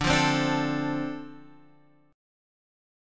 Dm7 chord